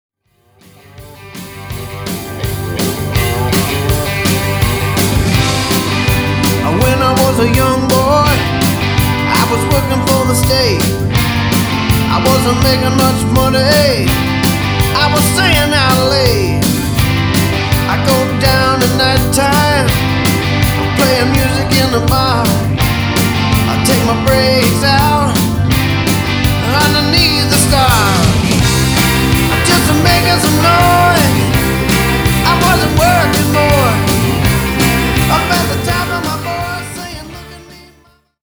The theme for this release is Red Hot Guitar
Vocals & Guitar
Organ & Piano